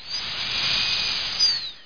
DENTIST.mp3